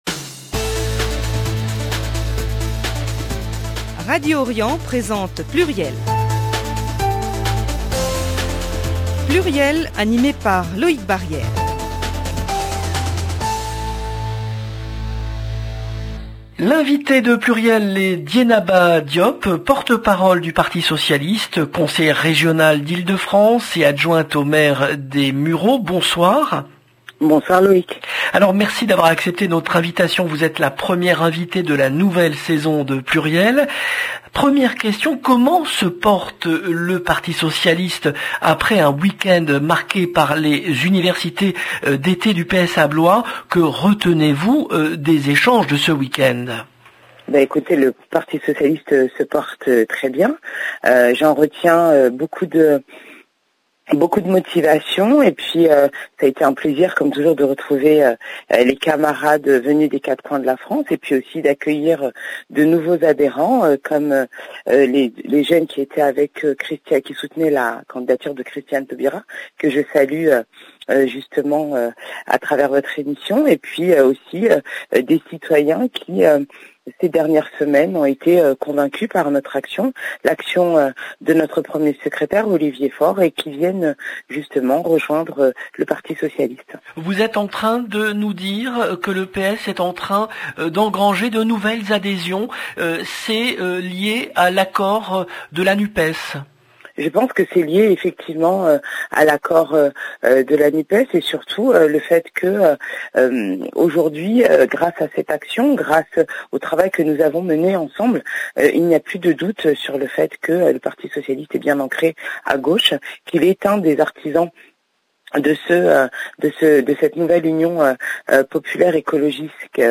Dieynaba Diop PS Parti socialiste 29 août 2022 - 17 min 50 sec Dieynaba Diop, porte-parole du Parti Socialiste LB PLURIEL, le rendez-vous politique du lundi 29 août 2022 L’invitée de PLURIEL est Dieynaba Diop, porte-parole du Parti Socialiste , conseillère régionale d’Ile-de-France et adjointe au maire des Mureaux. Lors de cette émission, elle a notamment exprimé son inquiétude face à l'explosion des prix. Elle a également évoqué le coût du pass navigo pour les lycéens d'Ile-de-France : 350 euros par adolescent à payer en une seule fois d’ici la fin septembre. 0:00 17 min 50 sec